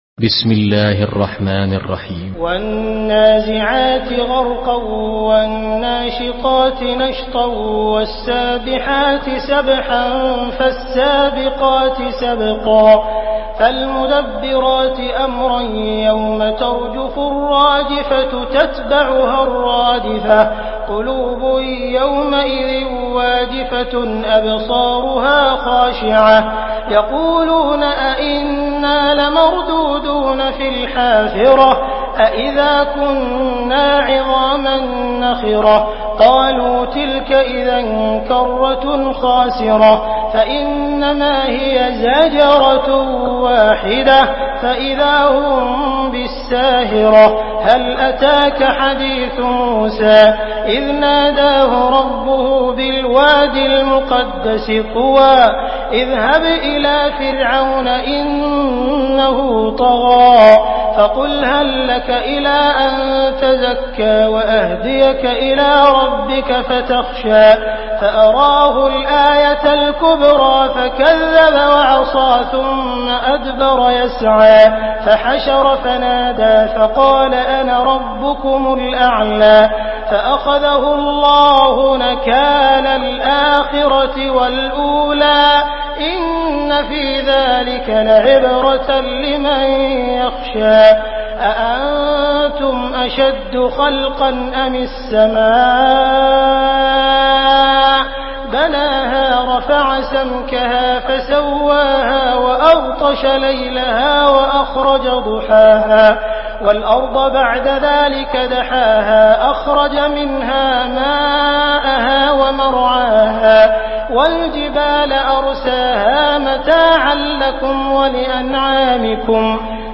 Surah النازعات MP3 in the Voice of عبد الرحمن السديس in حفص Narration
مرتل